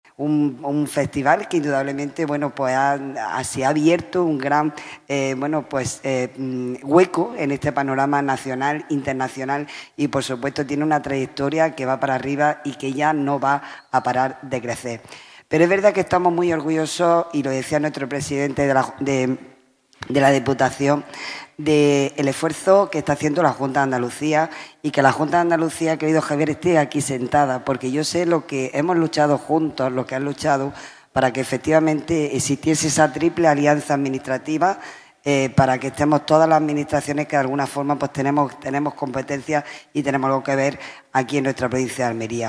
El Patio de Luces de Diputación ha acogido la presentación de la XXIV edición de FICAL, impulsado por la Institución Provincial, con el apoyo del Ayuntamiento de Almería y la Junta de Andalucía
ARANZAZU-MARTIN-DELEGADA-GOBIERNO-JUNTA-PRESENTACION-FICAL-2025.mp3